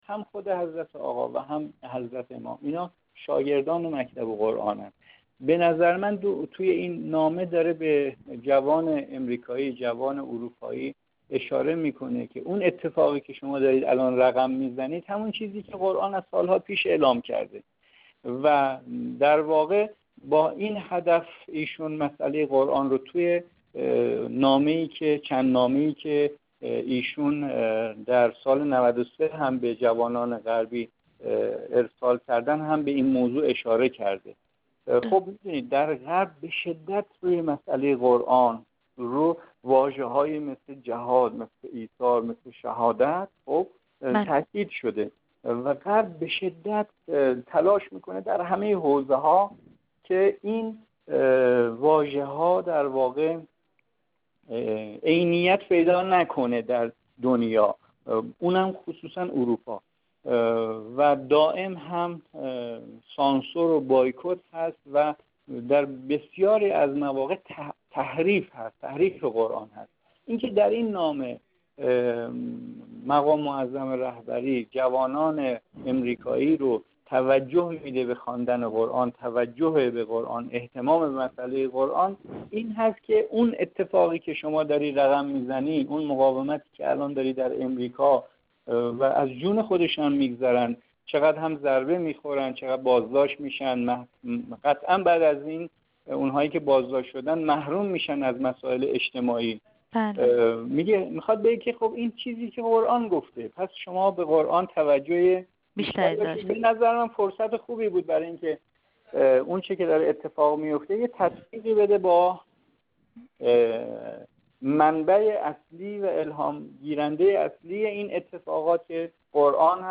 کارشناس مسائل غرب آسیا